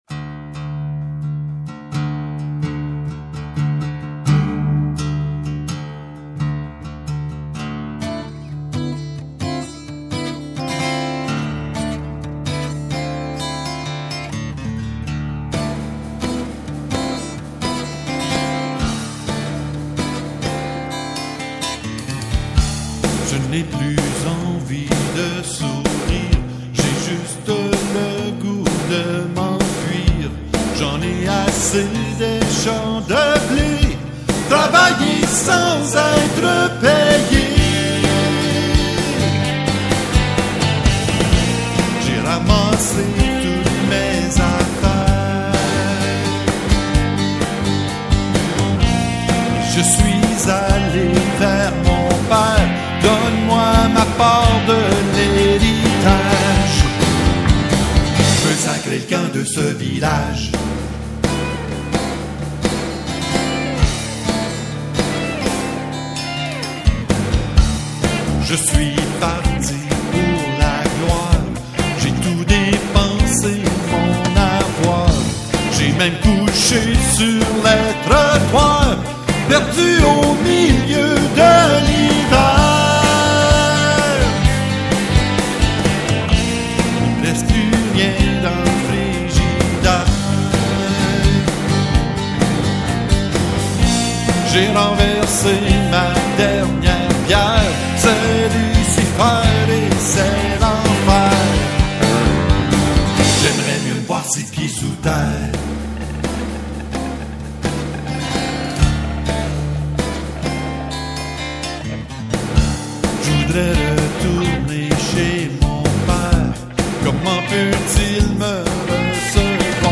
Chants divers
l_enfant_prodigue_chant.mp3